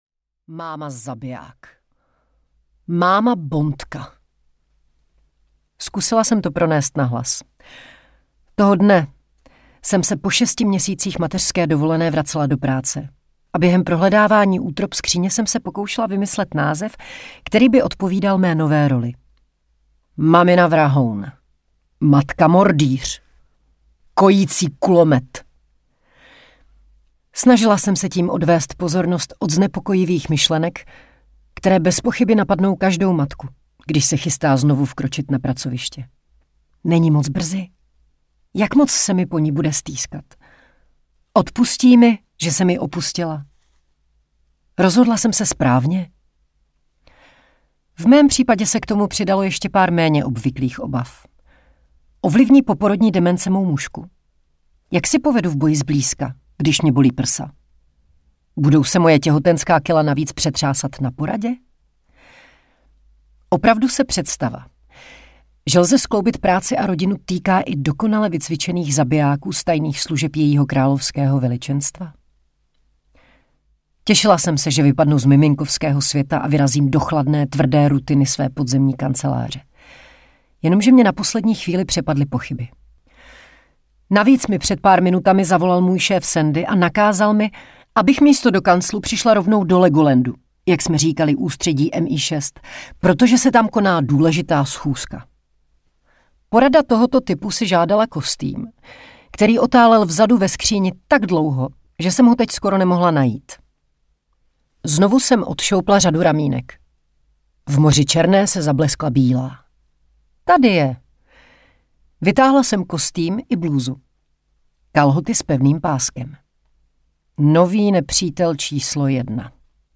Máma zabiják audiokniha
Ukázka z knihy
• InterpretIva Pazderková